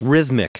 Prononciation du mot rhythmic en anglais (fichier audio)
rhythmic.wav